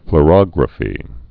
(fl-rŏgrə-fē, flô-, flō-)